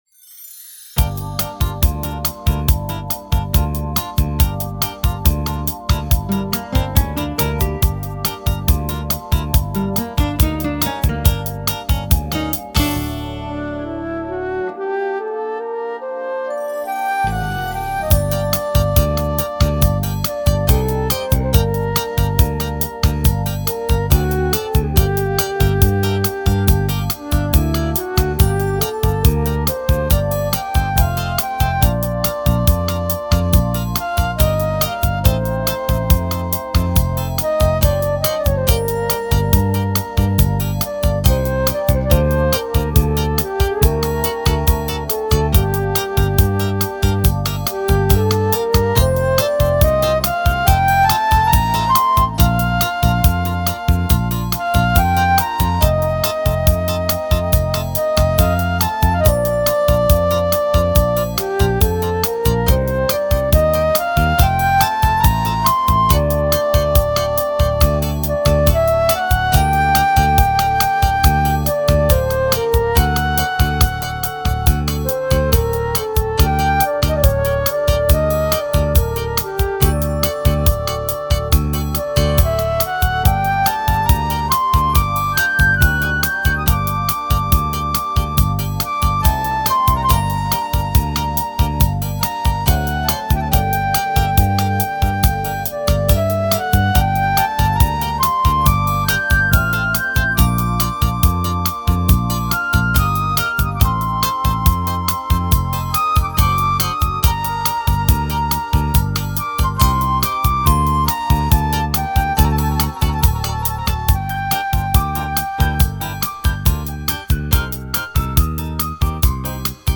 В той же современной обработке